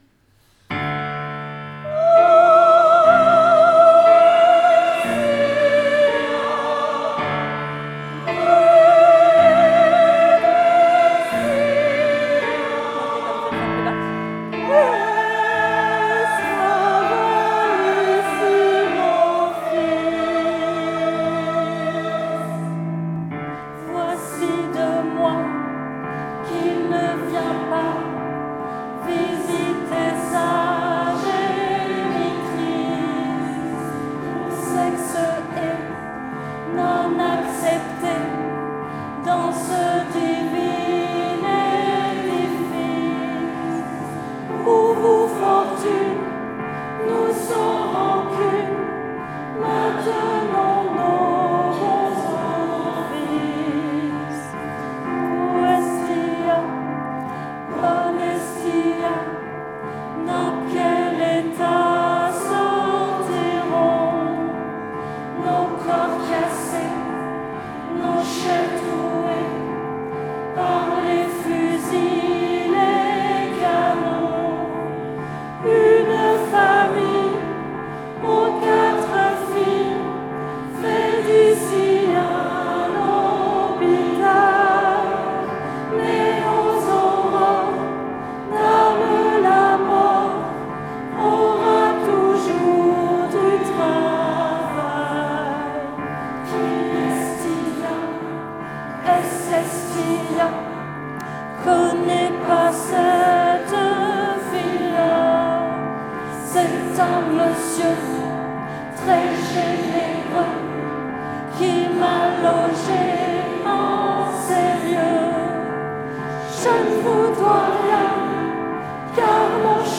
performance vidéo
pem15_CPS_choeur.mp3